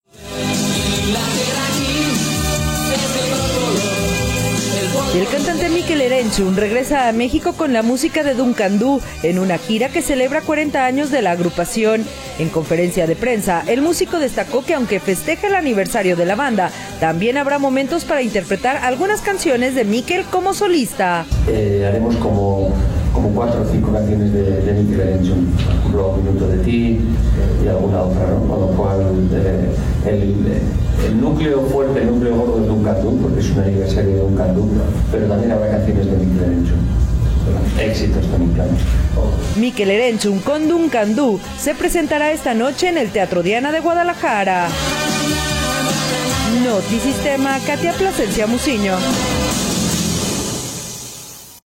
El cantante Mikel Erentxun regresa a México con la música de Duncan Dhu, en una gira que celebra 40 años de la agrupación. En conferencia de prensa, el músico destacó que, aunque festeja un aniversario de la banda, también habrá momentos para interpretar algunas canciones de Mikel como solista: